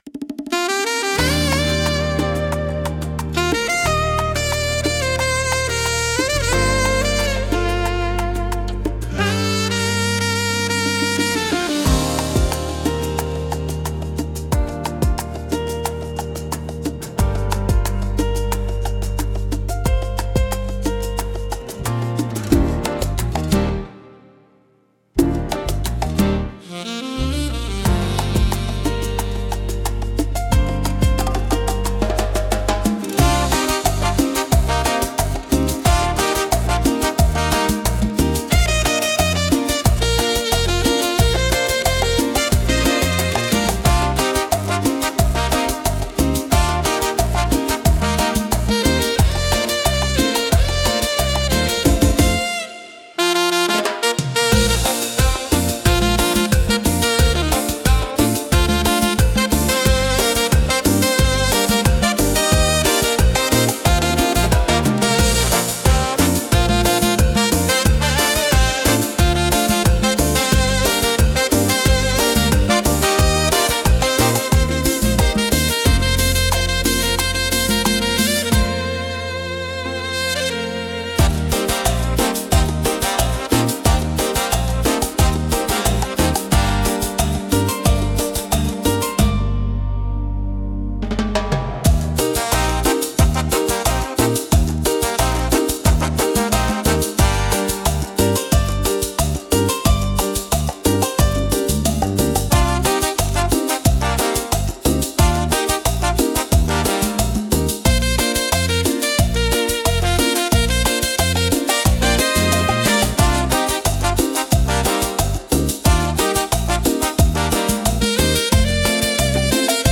música e arranjo: IA) instrumental 4